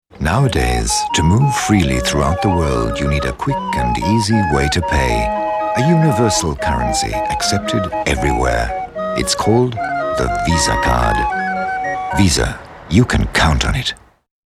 Well, I'd say it's deep, warm, persuasive, with sincerity and authority - but why not have a listen and judge for yourself?
Sprecher englisch / britisch.
Sprechprobe: Sonstiges (Muttersprache):